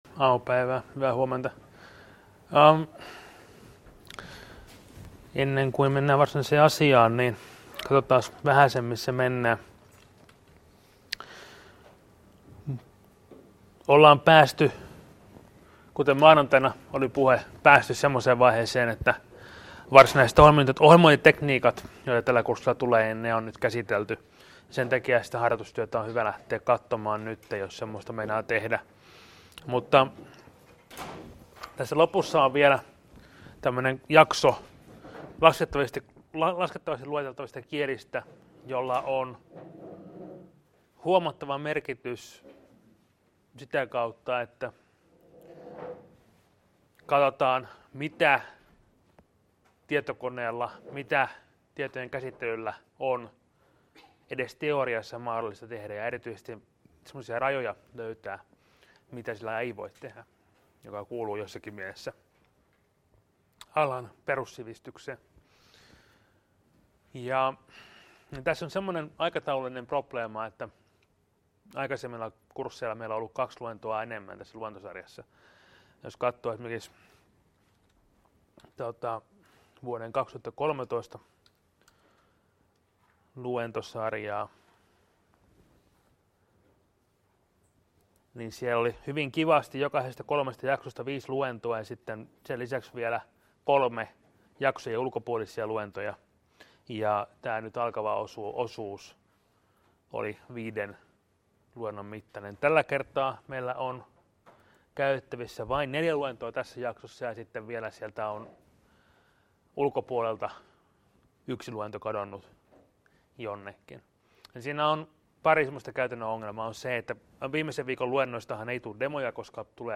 Luento 3.12.2015 — Moniviestin